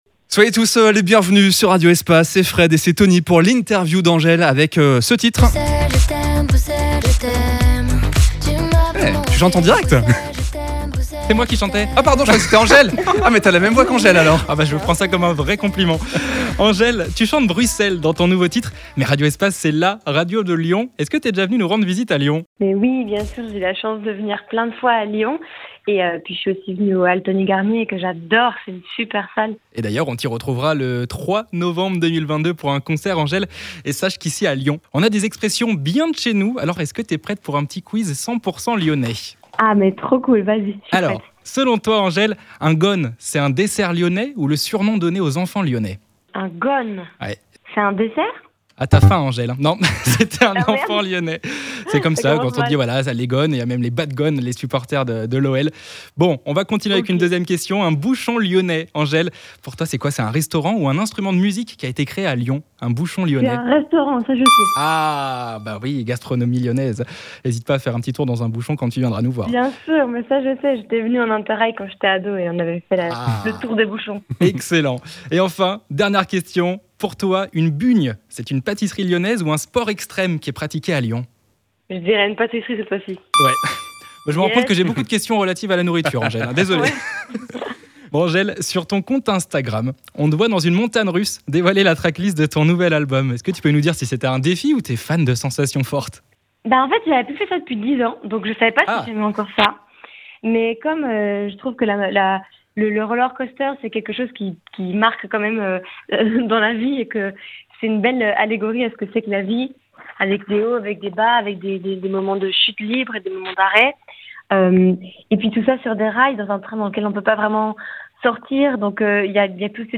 Découvrez l'interview diffusée sur Radio Espace